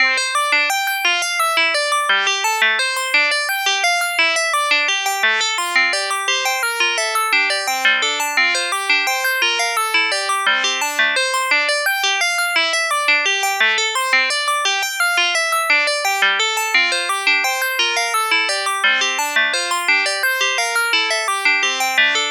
レトロゲームにありそうなビット感、ピコピコ電子音のチップチューン。